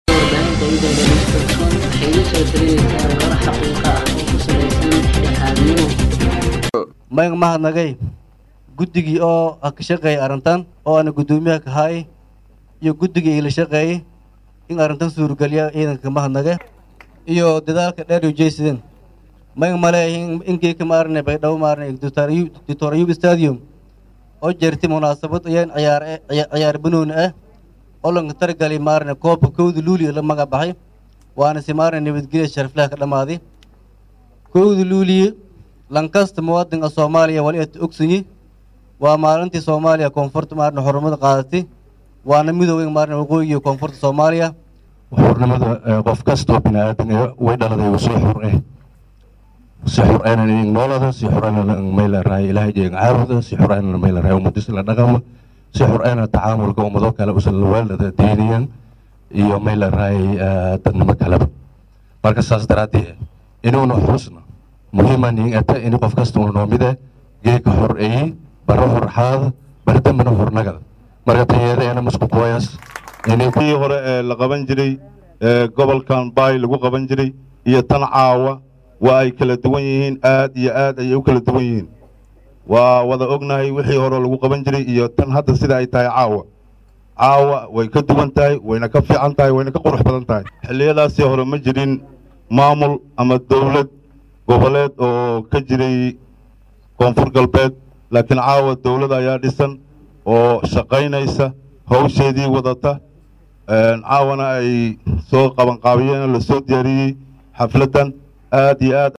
Dhageyso:Khudbadii Gudoomiyaha Gobolka Bay iyo Kan Degmada Baydhabo Ee Xuska 1da Luulyo
Baydhabo(INO)-Magaalada Baydhabo waxaa habeenkii xalay ahaa lagu qabtay munaasabad ballaaran oo loogu dabaal degaayay 55 Sano guuradii kasoo wareegtay markii ay Gumeysiga Talyaaniga ka xuroobeen gobalada Koonfureed ee dalka, islamarkaana ay midoobeen gobalada Waqooyi iyo Koonfur ee Soomaaliya.
Halkan Ka Dhageyso Khudbadihii Gudoomiyaha Gobolka Bay, Kan Degmada Baydhabo iyo General Ibraahim Yarow.